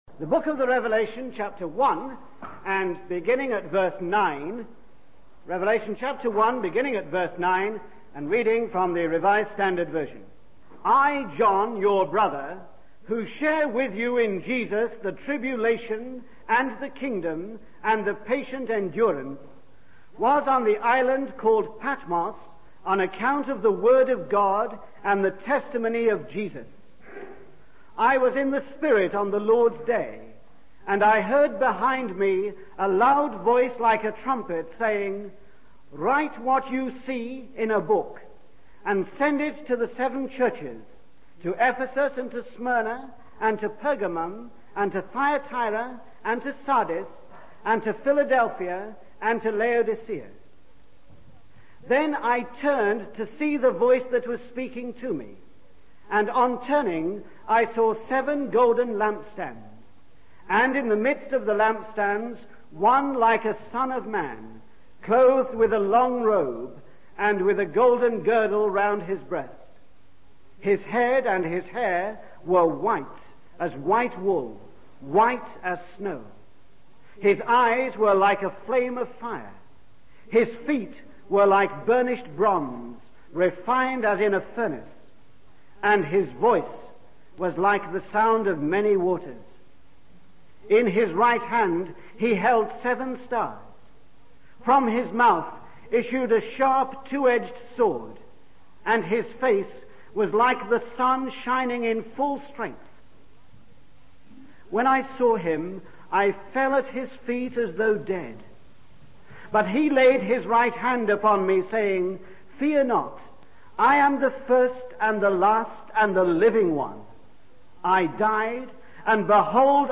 In this sermon, the speaker discusses the story of Gideon from the Bible and how God used him despite his ordinary status. The speaker emphasizes that it is not our own strategies but God's strategy that brings extraordinary results.